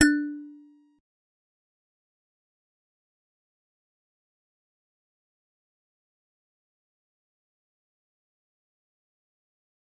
G_Musicbox-D4-pp.wav